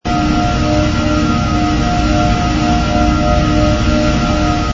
tractor_operating.wav